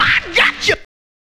DJP_VOX_ (8).wav